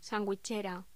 Locución: Sandwichera
voz
Sonidos: Voz humana